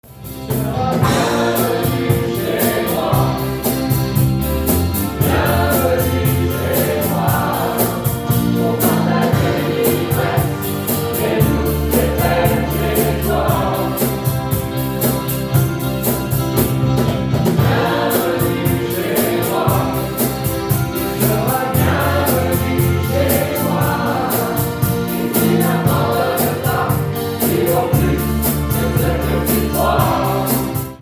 Avec son large répertoire de chansons francophones, le groupe Présence permet de rejoindre différents types de publics, les jeunes comme les moins jeunes.
Présentement composé de 27 musicien·ne·s et choristes passionné·e·s, le groupe Présence propose sa musique gratuitement, depuis 1988, pour soutenir les associations dans leur désir d’ajouter de la musique à leurs activités.
Après un réchauffement vocal, le groupe débute sa répétition avec le premier morceau du concert : « Bienvenue chez moi » de Florent Pagny :
Les choristes, les accordéonistes (à gauche), la pianiste et le guitariste du groupe Présence s‘échauffent durant la répétition.